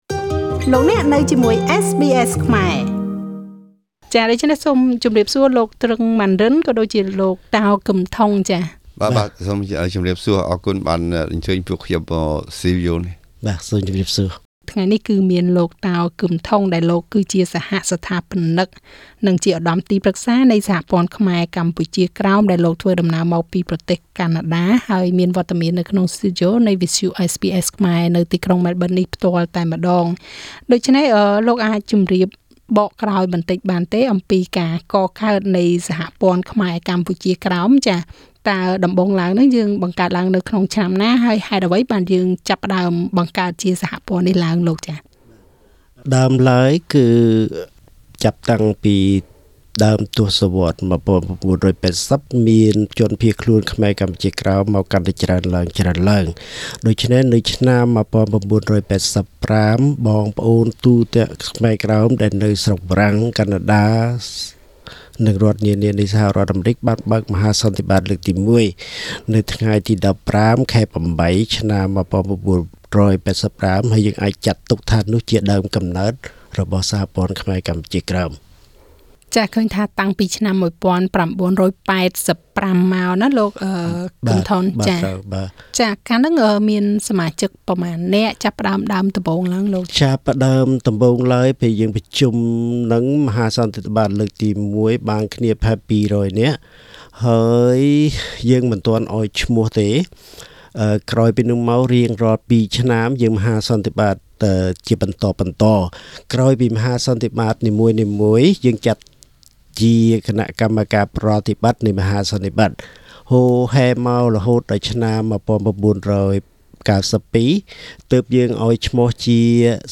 at SBS studio in Melbourne Source